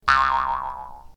bounce2.ogg